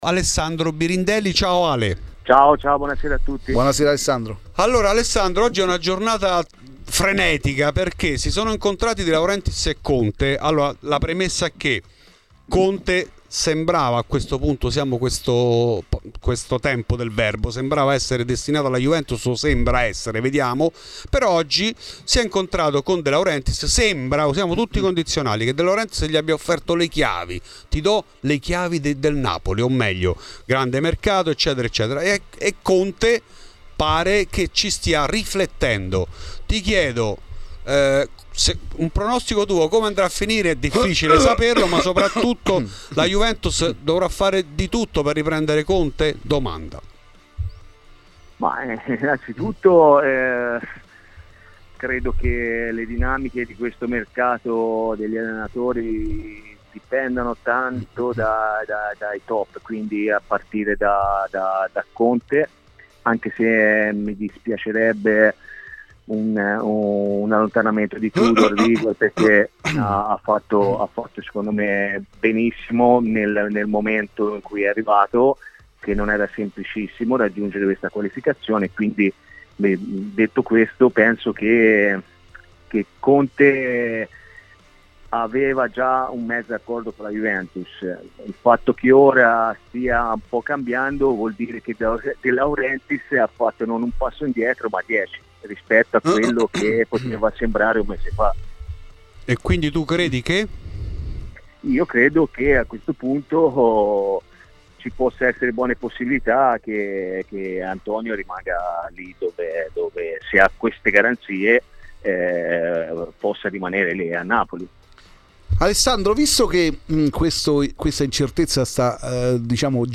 In ESCLUSIVA a Fuori di Juve Alessandro Birindelli.